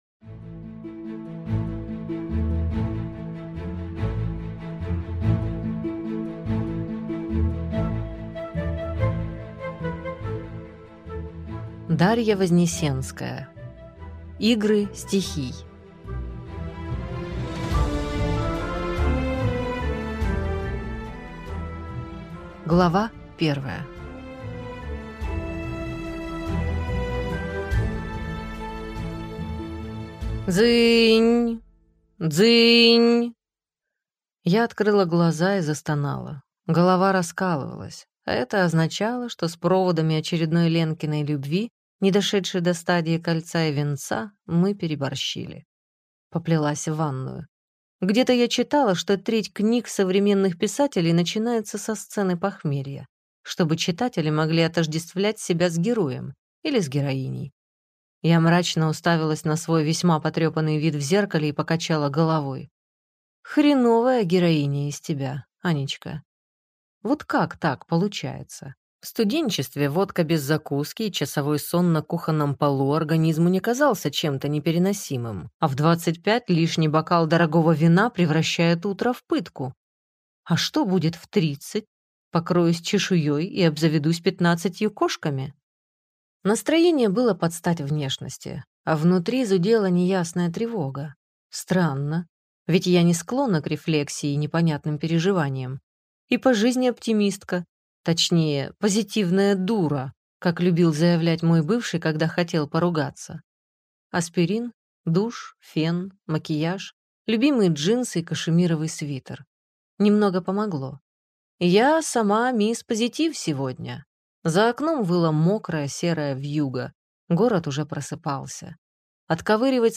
Аудиокнига Игры стихий | Библиотека аудиокниг